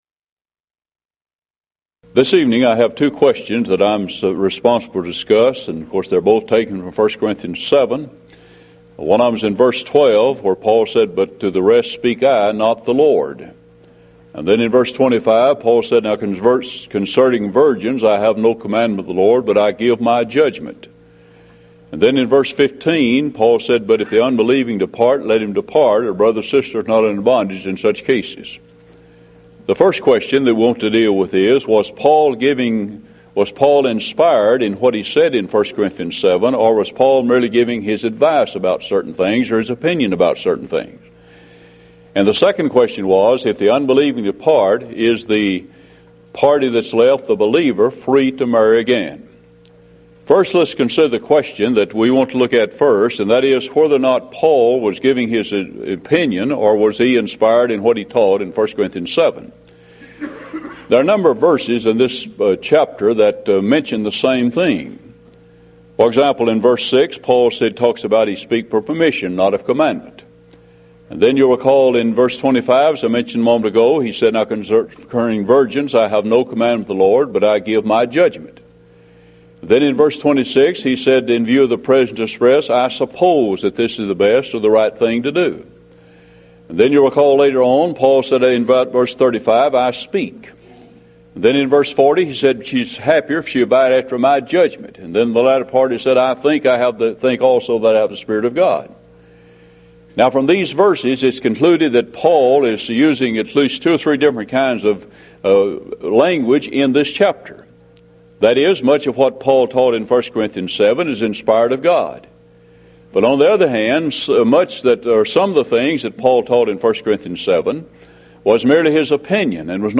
Event: 1995 Mid-West Lectures